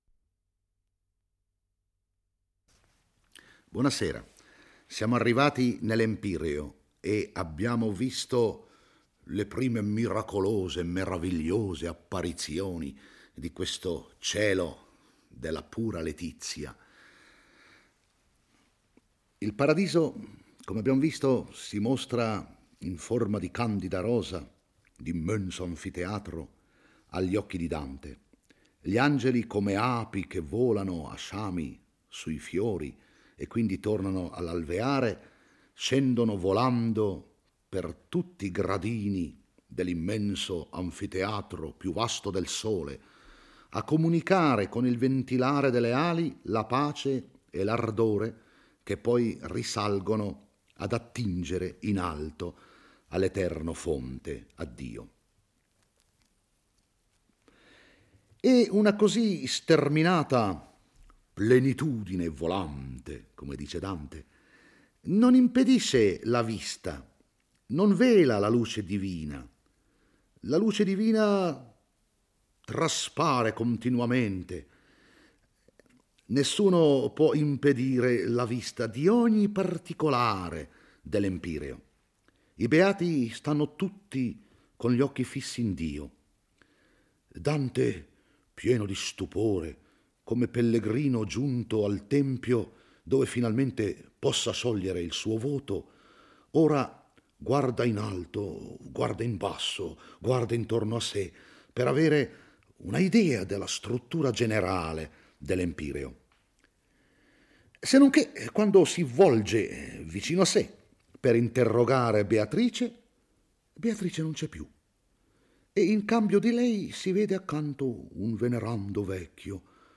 legge e commenta il XXXI canto del Paradiso. Dopo che i beati si sono mostrati a Dante in forma di candida rosa il poeta scorge gli Angeli che, cantando la gloria di Dio, appaiono come uno sciame di api in volo: essi hanno il viso color di fiamma, le ali dorate e il resto della figura più bianco della neve. Tutto il regno felice si volge al Creatore e Dante pronuncia una invocazione alla Trinità perché possa volgersi alla terra.